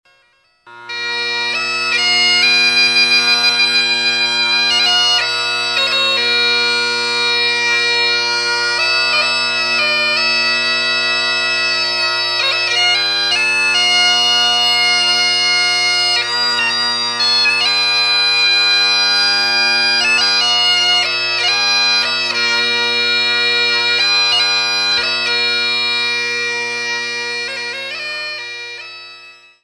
La Bagpipe